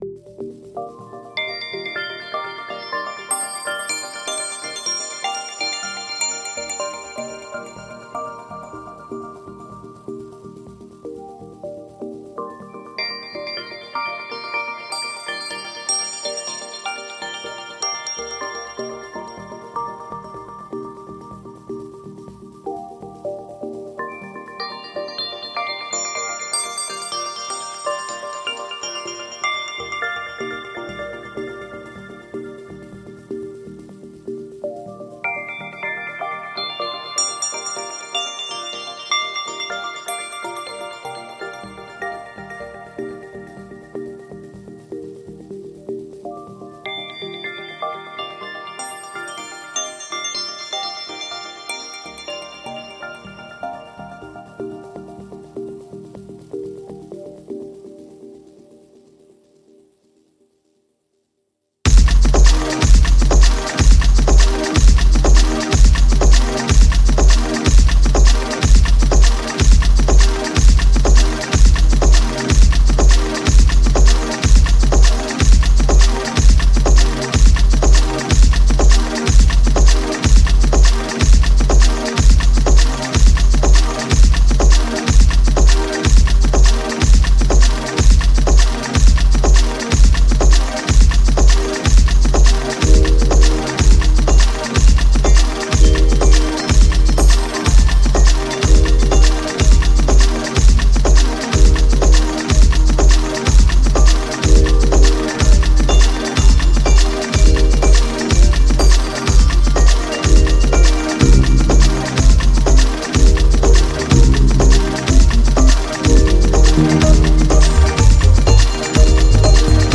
Electronic Indie